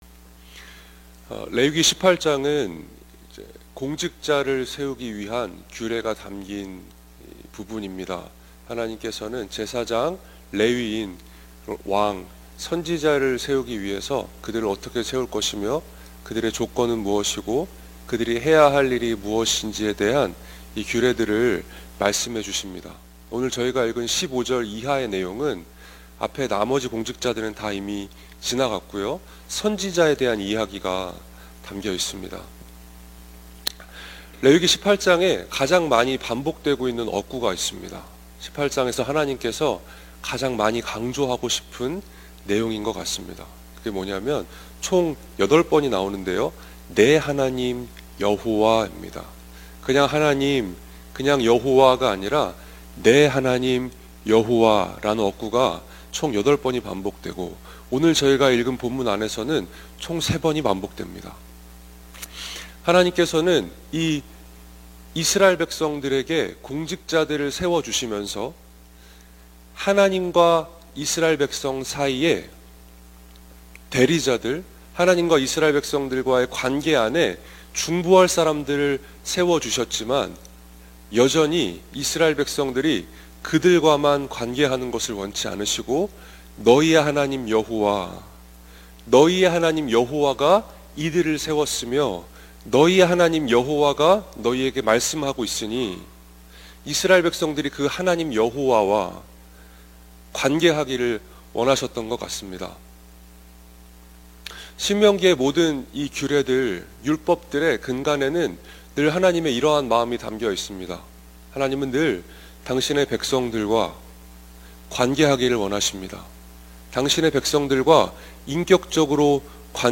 예배: 평일 새벽